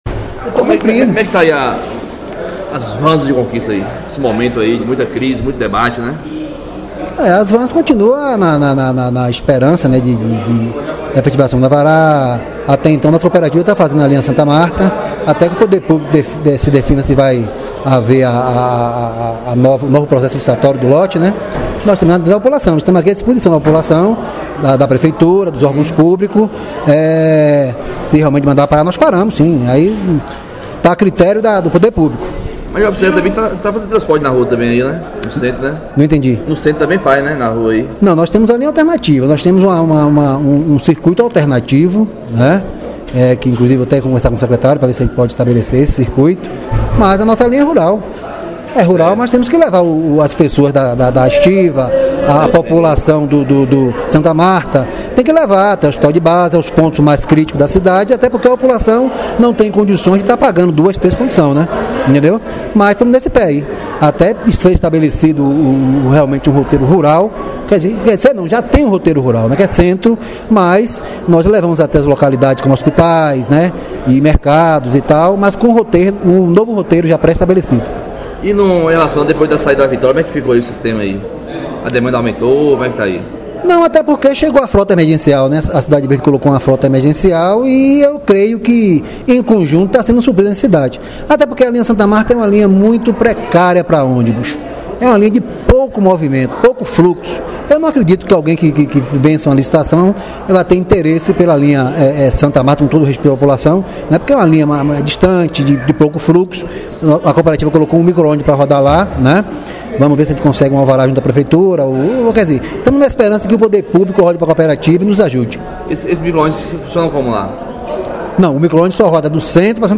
Entrevista Exclusiva: Cooperativa terá dois micro-ônibus no Transporte de ageiros em Vitória da Conquista 1b6e4x